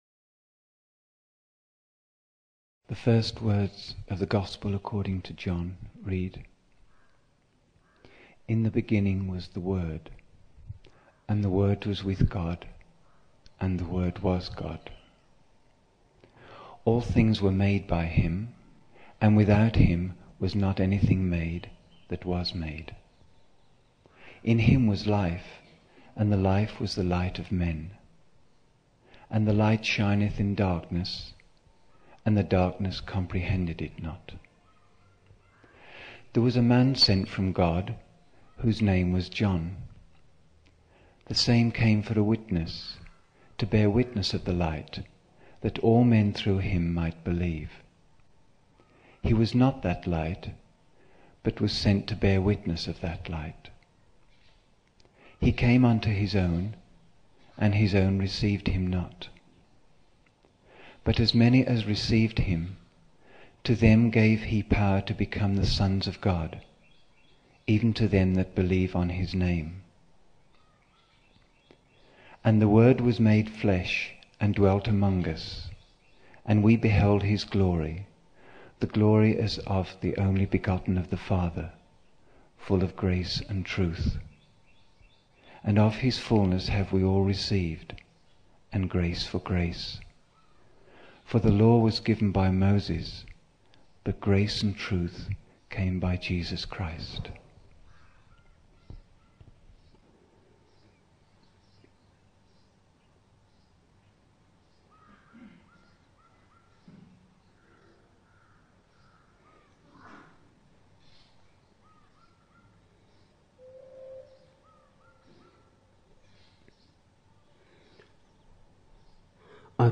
21 October 1975 morning in Buddha Hall, Poona, India